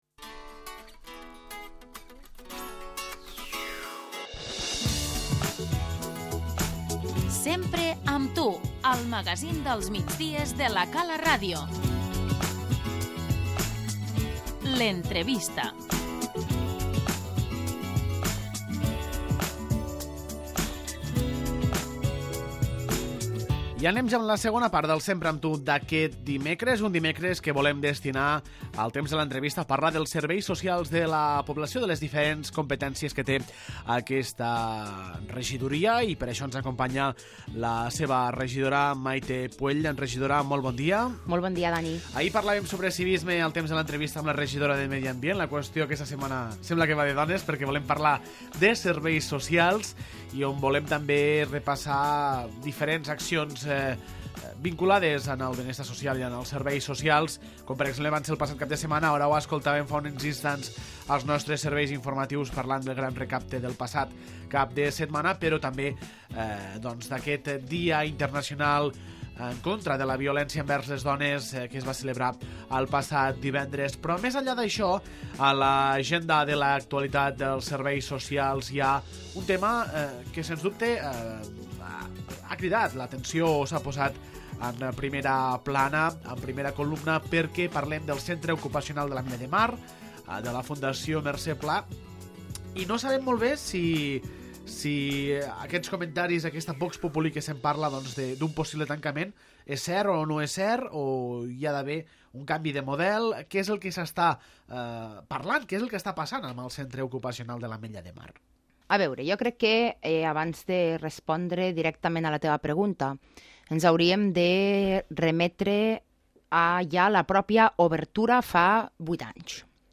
L'entrevista - Mayte Puell, regidora de Benestar Social
La regidora de Benestar Social, Mayte Puell, ens acompanya a l'estudi per fer un repàs de temes relacionats amb els Serveis Socials a l'Ametlla de Mar. En especial, posem el focus a la situació del Centre Ocupacional i la seva continuitat.